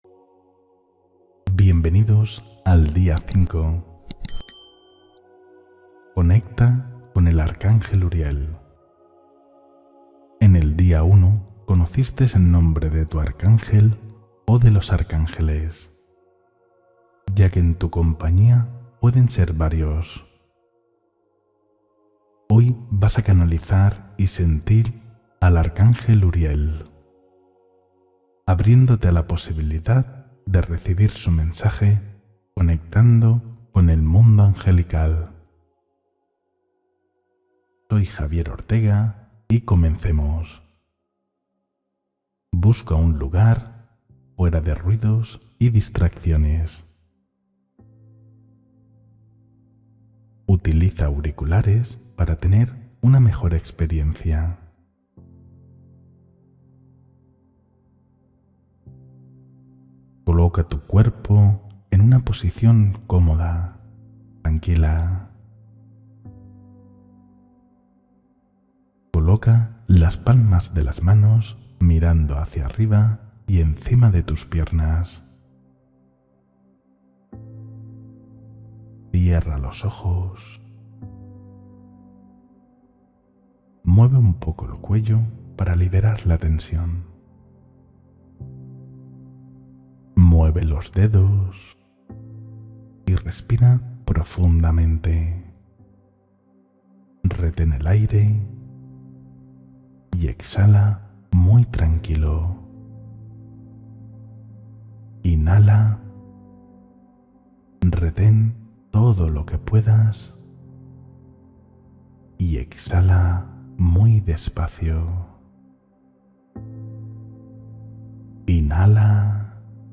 Meditación con el arcángel Uriel: claridad y serenidad para tu vida – Día 5